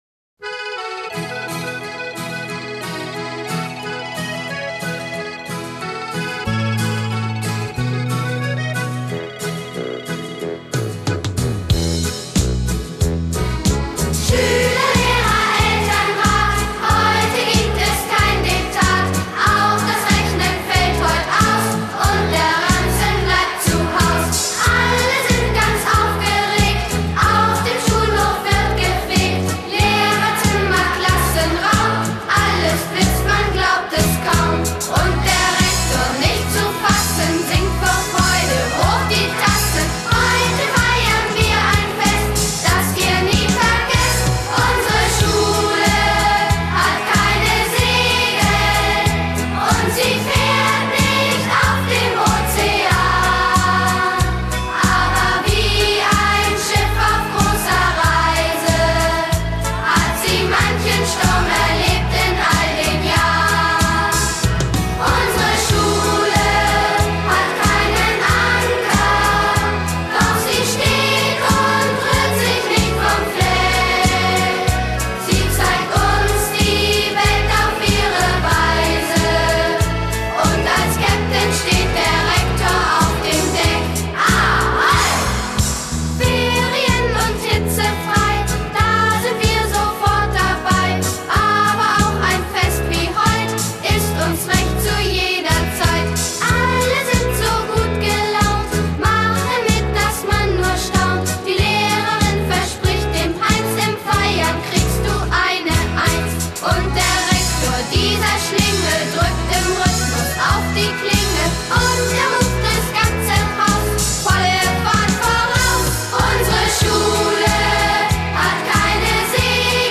unserer Schule (mit Gesang).mp3